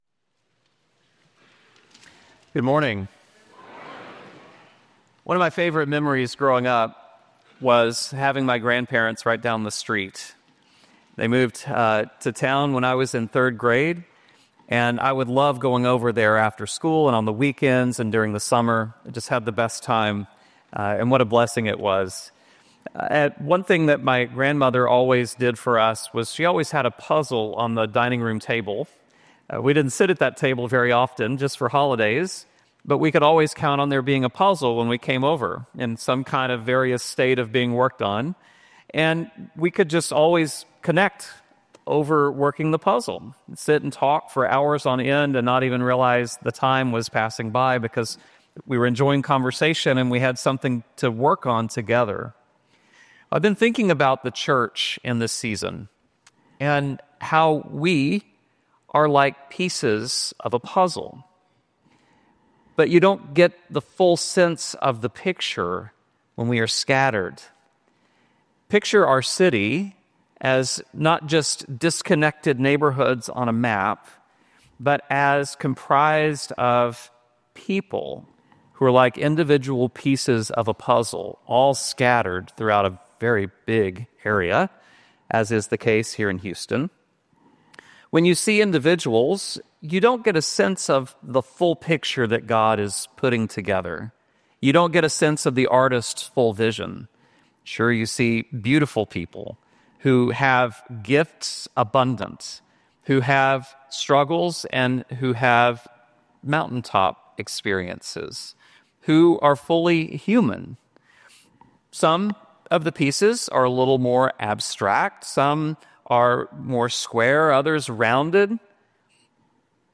Preacher
Service Type: Traditional